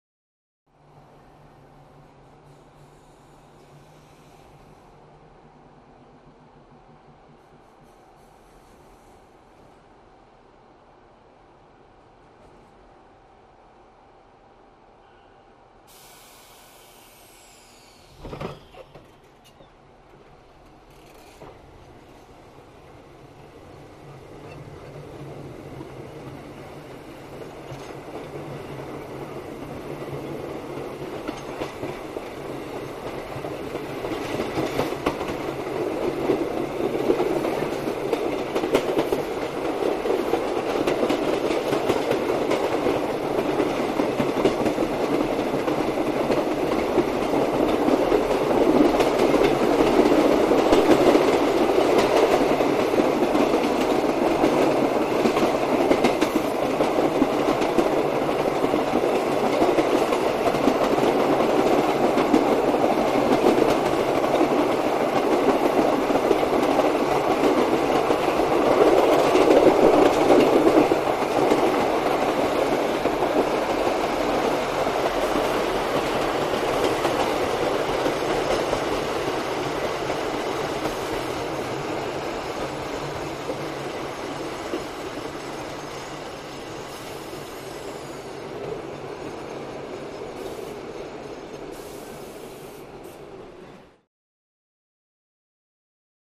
BART Subway
Start / Away ( Exterior ); Bart Train Starts And Runs Through Tunnel.